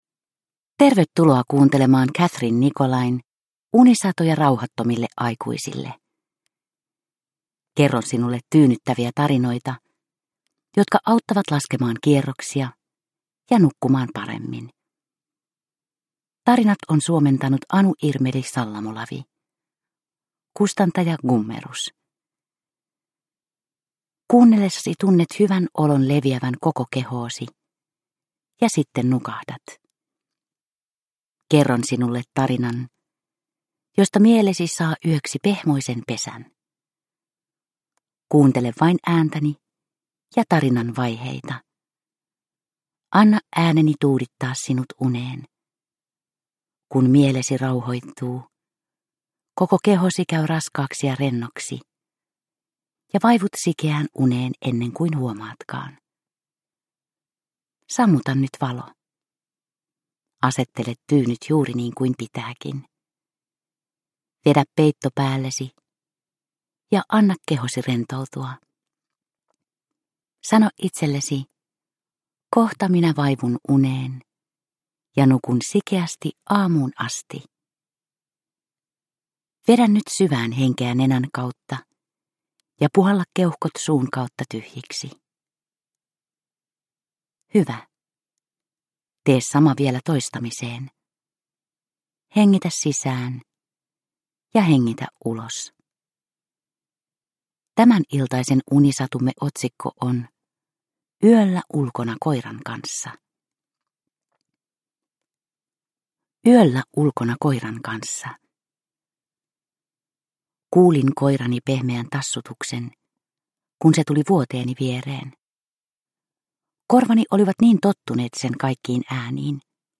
Unisatuja rauhattomille aikuisille 7 - Yöllä ulkona koiran kanssa – Ljudbok – Laddas ner